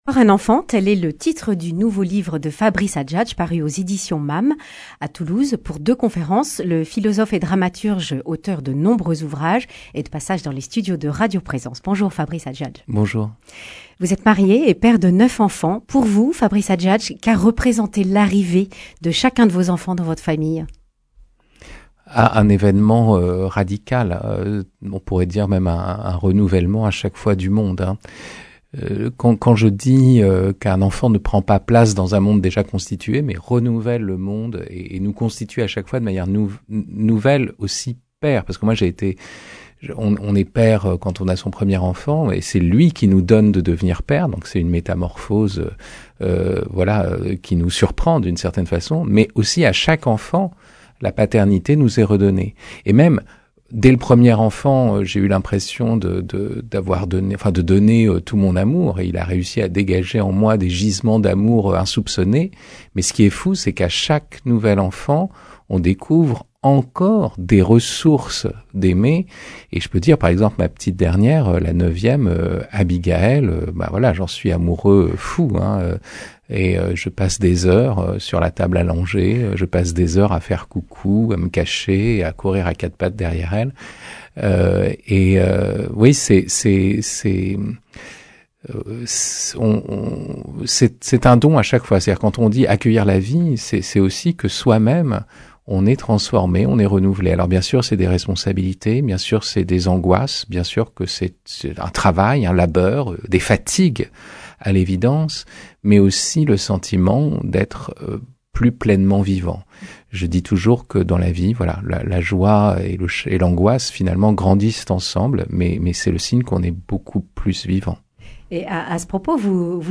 Accueil \ Emissions \ Information \ Régionale \ Le grand entretien \ Encore un enfant ?